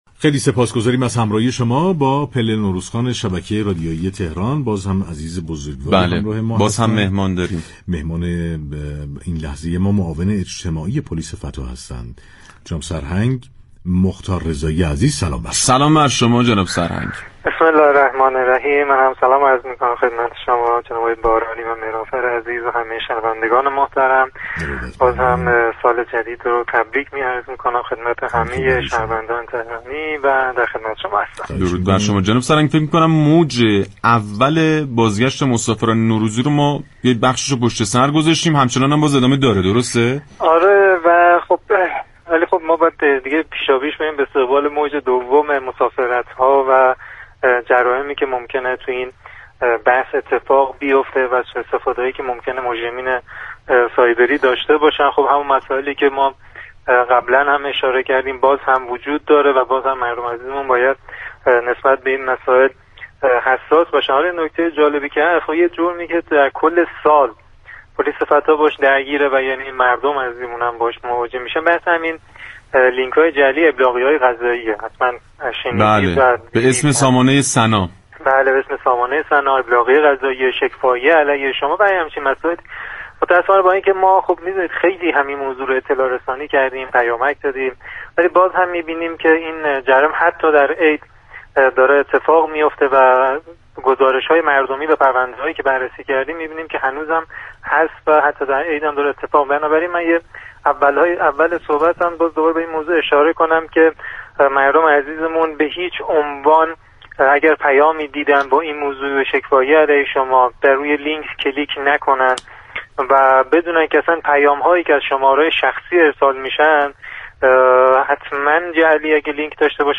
معاون اجتماعی پلیس فتای فراجا در گفت و گو با رادیو تهران از اجرای طرح گشت اسكیمر پلیس فتا در ایام نوروز خبر داد و گفت: همكاران ما در این ایام، بویژه در مكان‌هایی كه امكان كلاهبرداری اسكیمرها در آنها بیشتر است گشت محسوس و نامحسوس دارند.